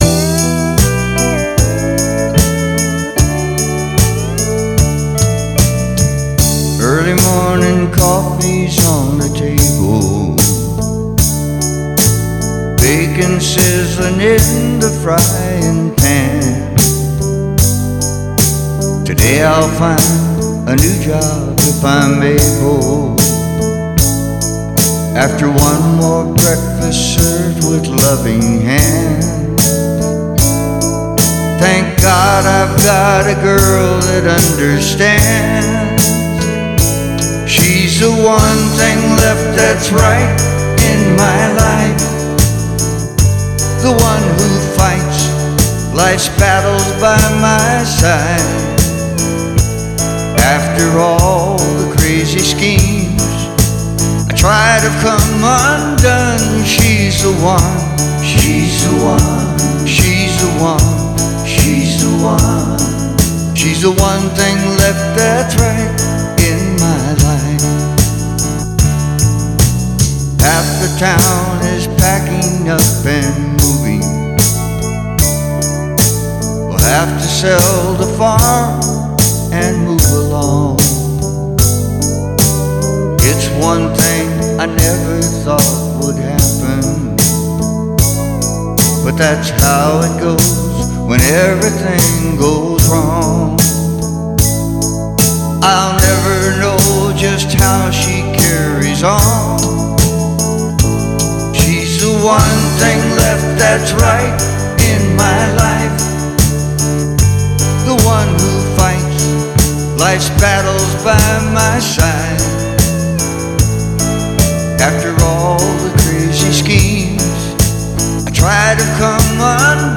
vocals, keyboards
MSA pedal steel